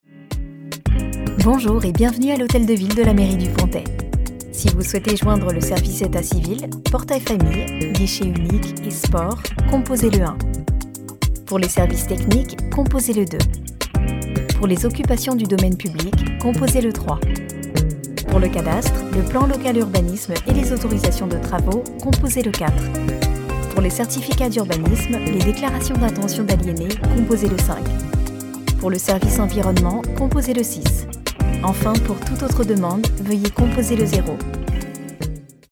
Natural, Deep, Playful, Soft, Commercial
Telephony